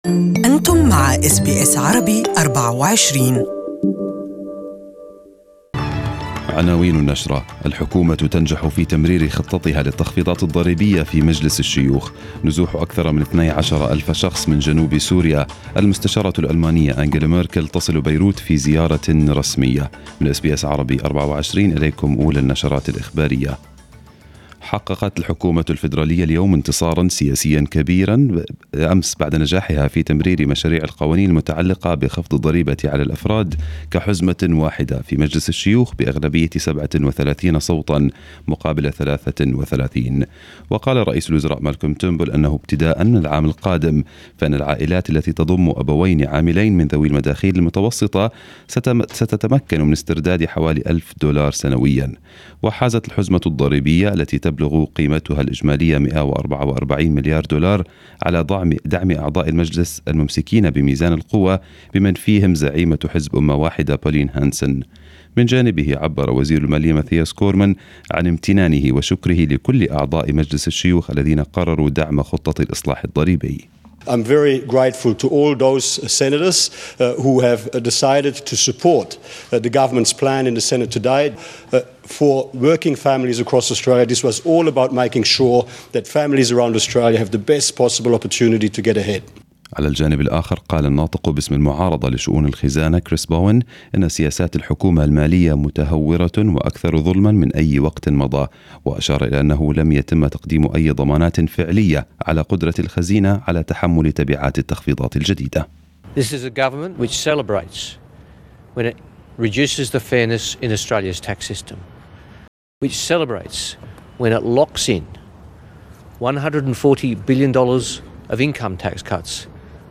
Arabic News Bulletin 22/06/2018